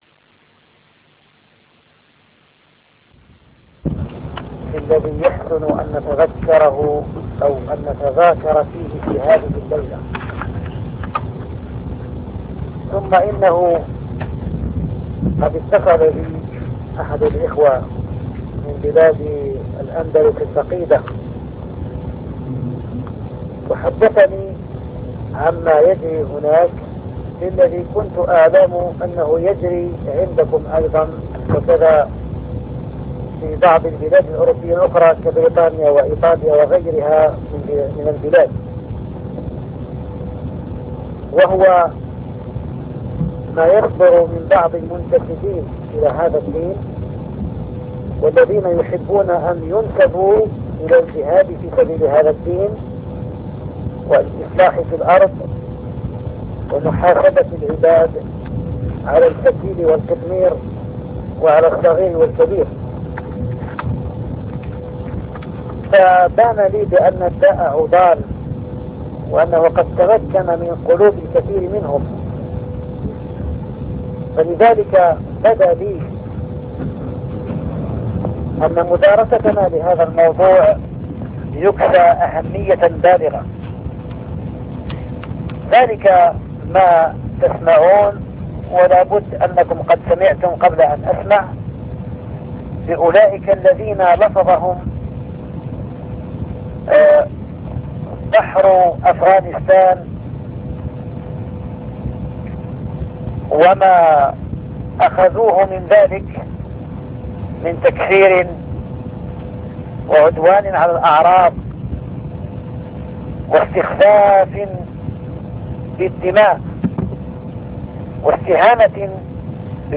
Cours du 21/04/2001 � Sartrouville